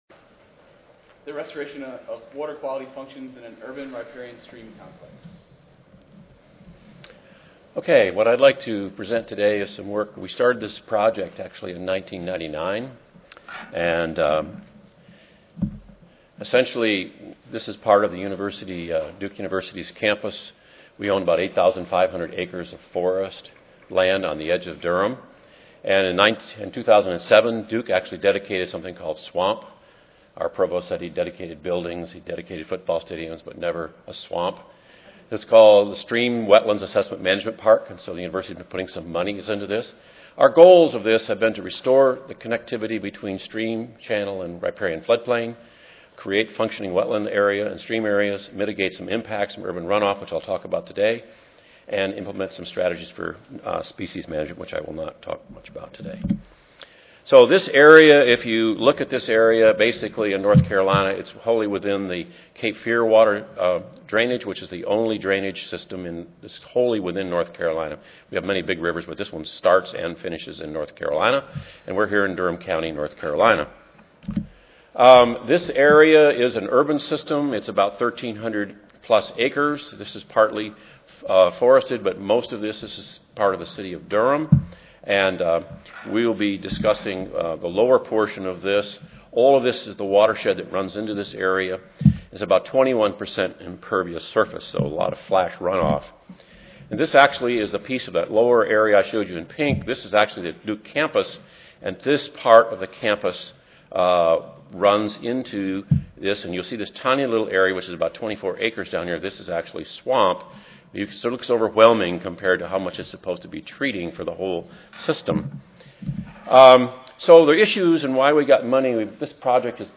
S10 Wetland Soils Session: Treatment Wetlands and Vegetative Strips for Water Quality Improvement (ASA, CSSA and SSSA Annual Meetings (San Antonio, TX - Oct. 16-19, 2011))
Duke University Recorded Presentation Audio File